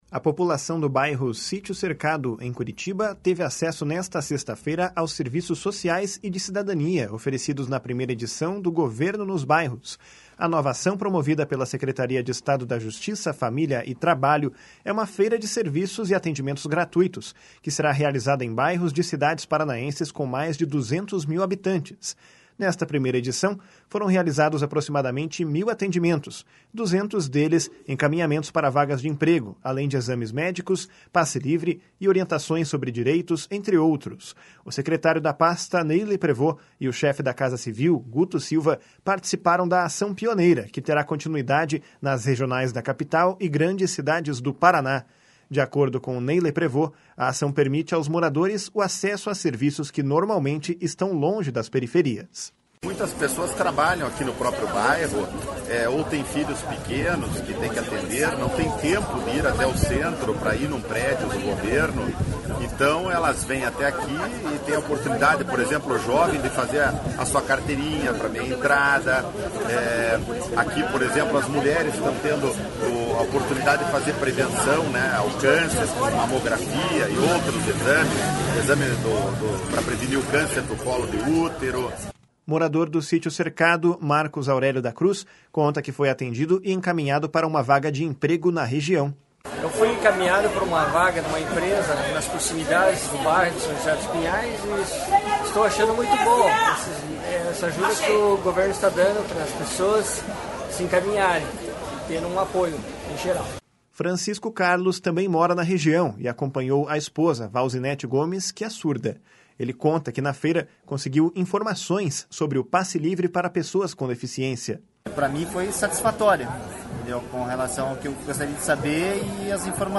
De acordo com Ney Leprevost, a ação permite aos moradores o acesso a serviços que normalmente estão longe das periferias. // SONORA NEY LEPREVOST //